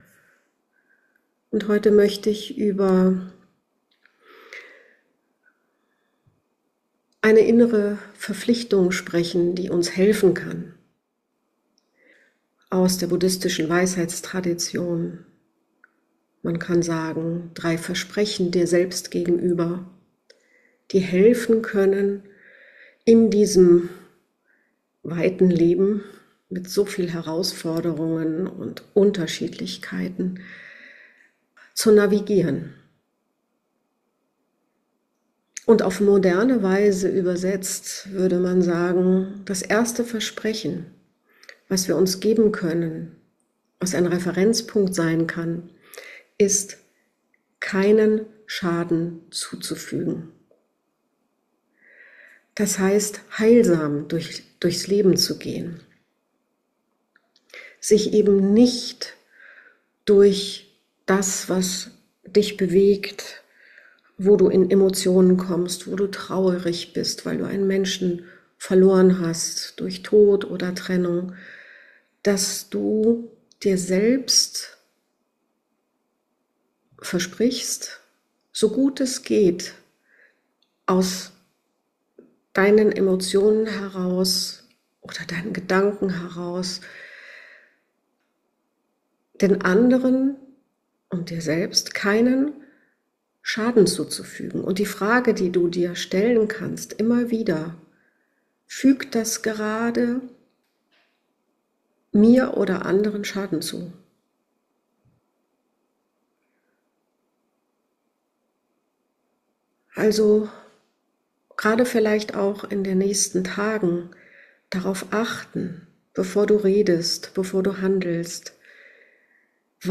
Drei Versprechen aus der buddhistischen Weisheitspraxis können uns durch Zeiten von Neuorientierung tragen: Heilsam leben, Mitgefühl kultivieren und die Wirklichkeit annehmen, wie sie ist. In diesem kurzen Dharma Talk spreche ich darüber, wie diese drei Ausrichtungen im Alltag wirksam werden können – als Orientierung, als innere Haltung und als Kompass, der sich immer wieder neu justieren lässt.